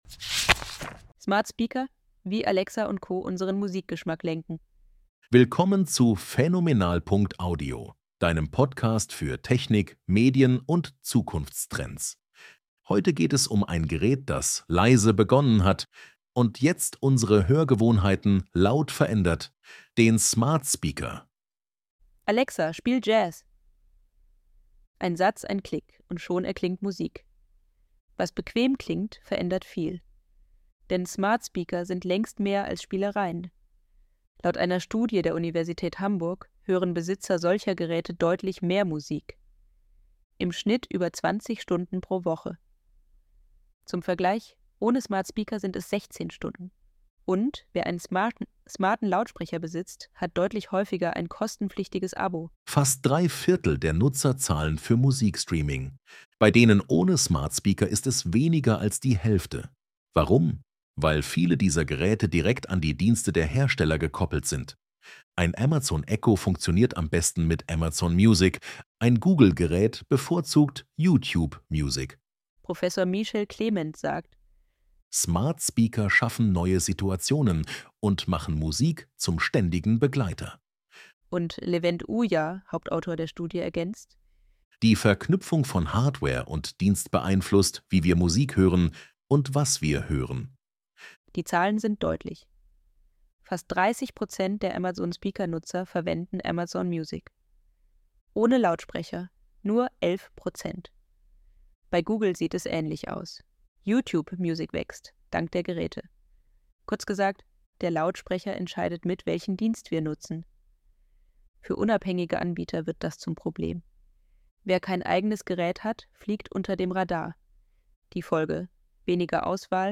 ElevenLabs_Probepodc.mp3